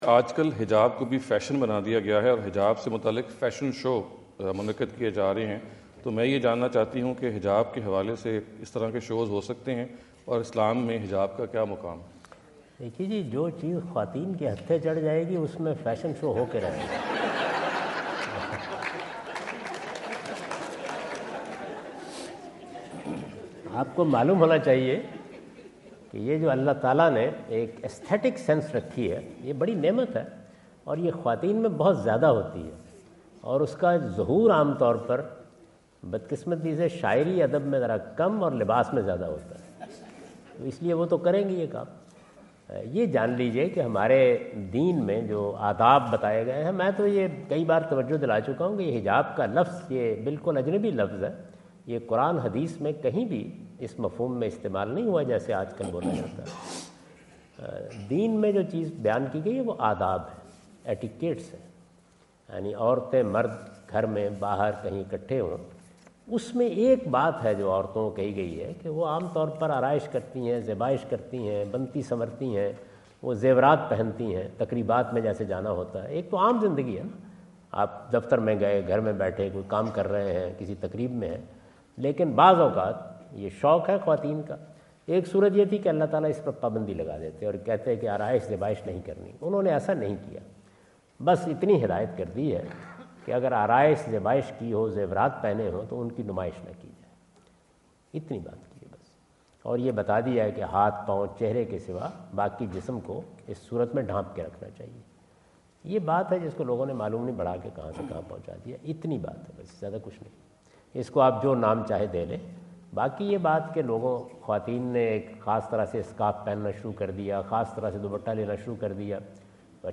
Javed Ahmad Ghamidi answer the question about "ٰFashion Shows for Promotion of Hijab" asked at The University of Houston, Houston Texas on November 05,2017.
جاوید احمد غامدی اپنے دورہ امریکہ 2017 کے دوران ہیوسٹن ٹیکساس میں "حجاب کے لیے فیشن شو کا انعقاد" سے متعلق ایک سوال کا جواب دے رہے ہیں۔